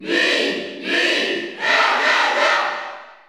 Category: Crowd cheers (SSBU)
Mii_Cheer_Spanish_PAL_SSBU.ogg.mp3